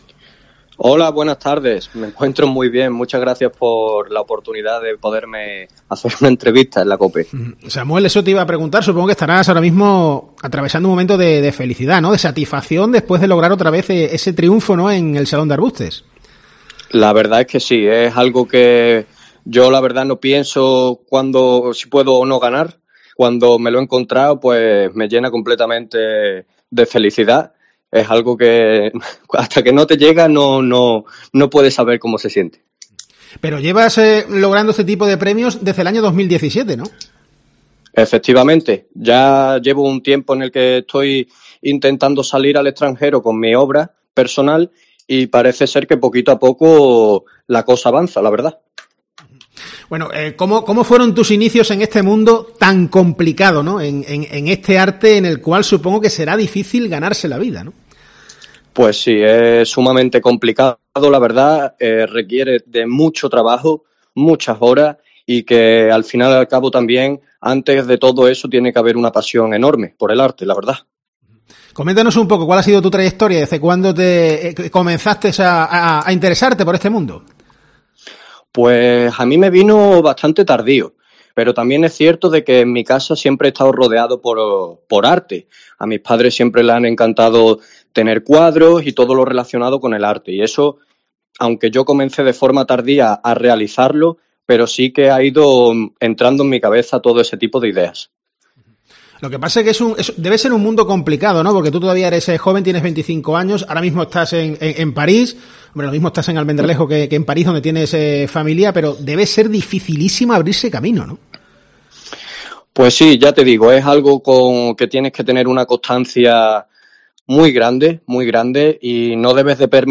En COPE, hemos entrevistado